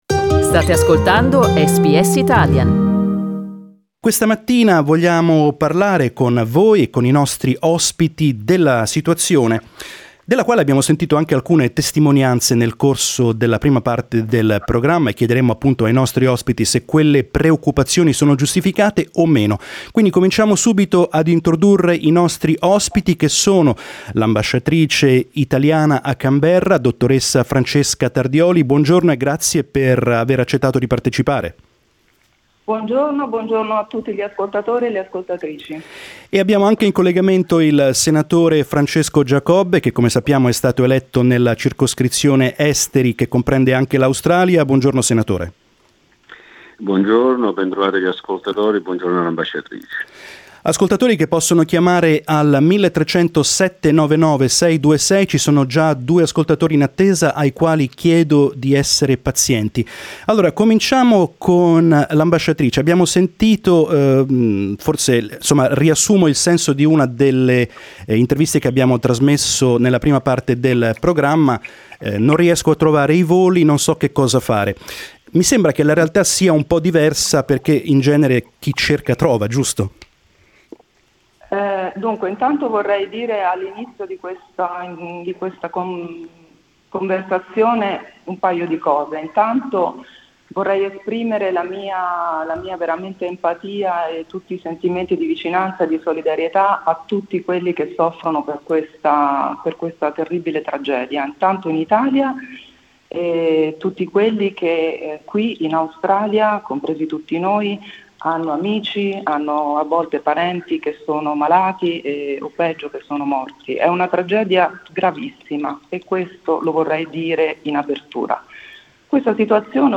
Ambassador Tardioli and Senator Giacobbe illustrated how to contact directly the diplomatic seats and answered questions from our listeners during our regular talkback.